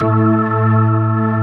Index of /90_sSampleCDs/AKAI S6000 CD-ROM - Volume 1/VOCAL_ORGAN/POWER_ORGAN
P-ORG2  A2-S.WAV